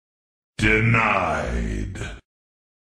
Denied Sound
meme